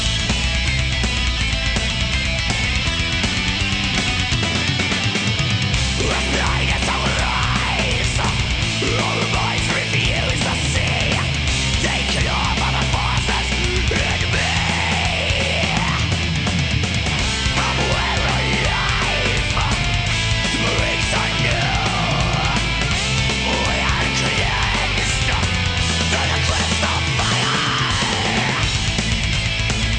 GTZAN-Genre / genres_original /metal /metal.00012.wav
metal.00012.wav